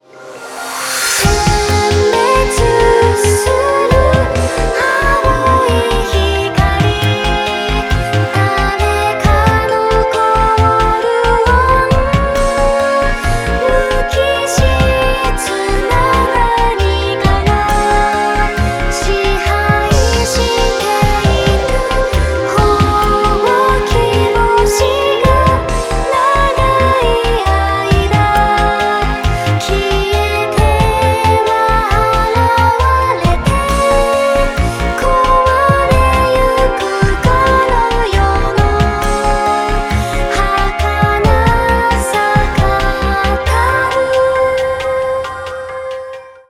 All Vocal,Lyrics,Chorus